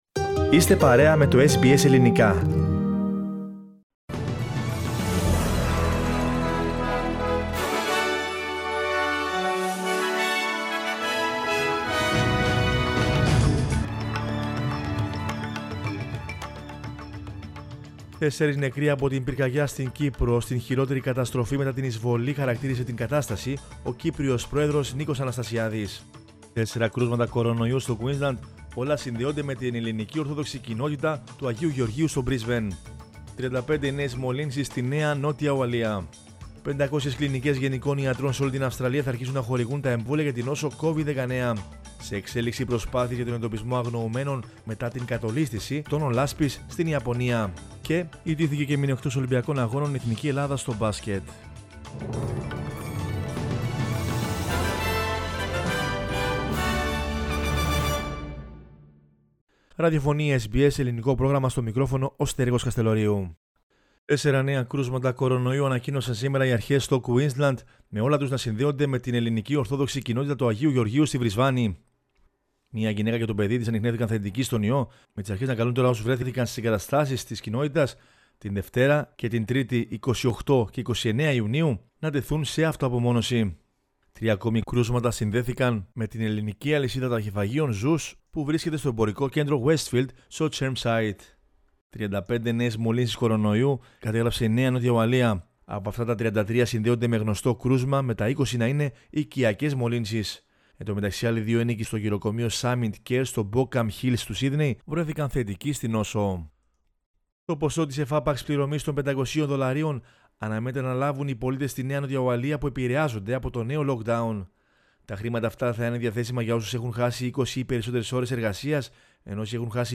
Δελτίο Ειδήσεων 05.07.21